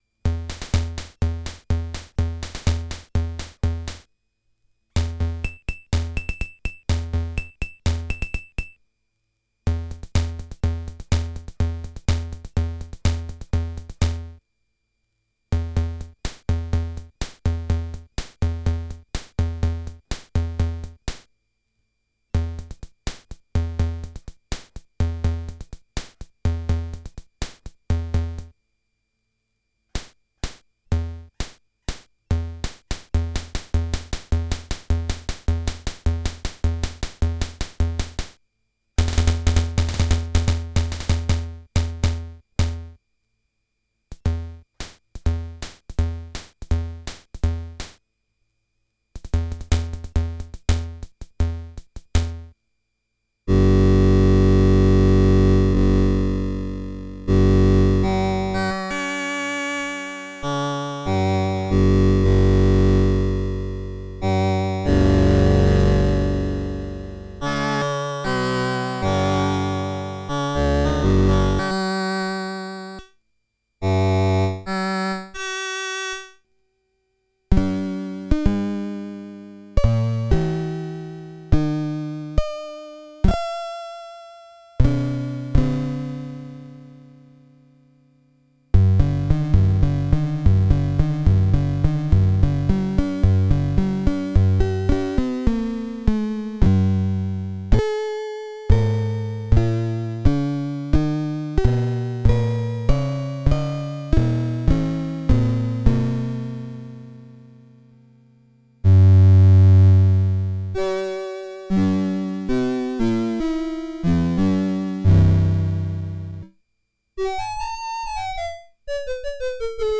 Really nice and almost sophisticated are the analog sounding drums: timid organ rhythm box style, a whisper of some old school Casio – very useful for minimal wavish stuff!
The keyboard sounds have an almost Commodore 64 SID chip atmosphere to them – you can switch on a vibrato to give them an even more soul stirring sentiment. The organ sound is beyond evil, jumbled up straight out of SATANS sleeve while the delicate piano has a narcotizing beauty.
Here is an mp3 going through the sounds: Toy Keyboard go back to main synthesizer site
toykeyboard.mp3